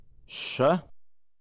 [ɕa]